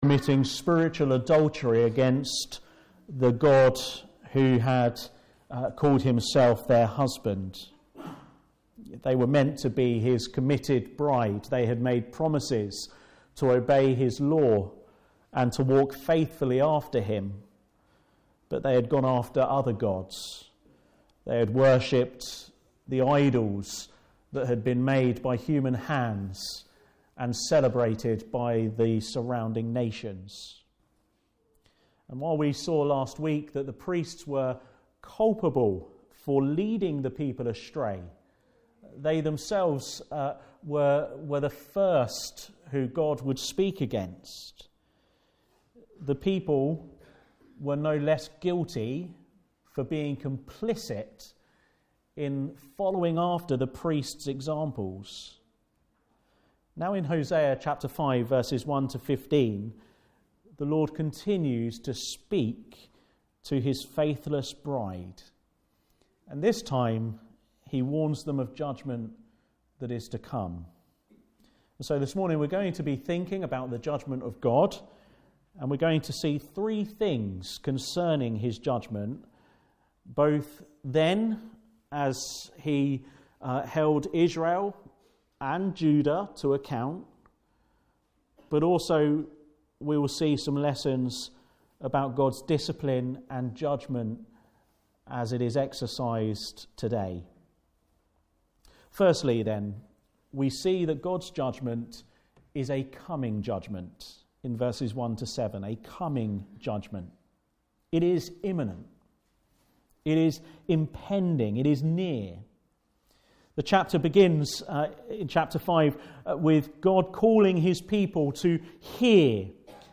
5-10 Service Type: Afternoon Service Special Service Did Jesus Really Rise From the Dead?